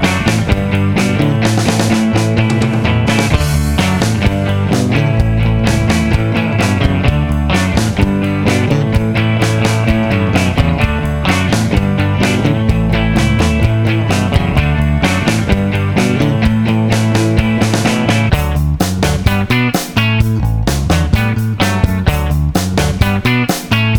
Two Semitones Down Pop (1960s) 2:23 Buy £1.50